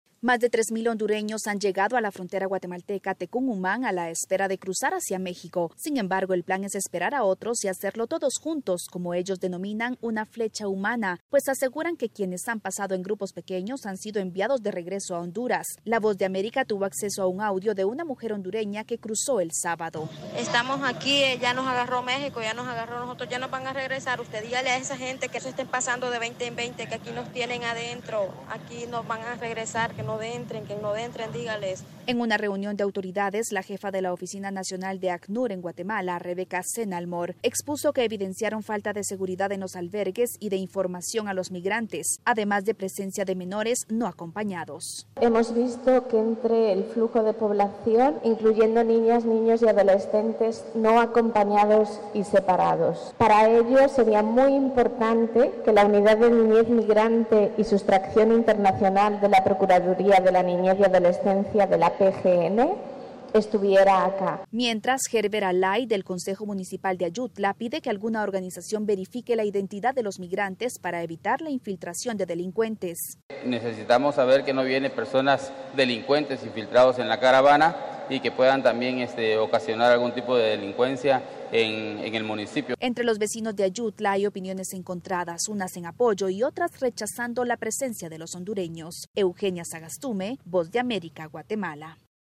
VOA: Informe de Guatemala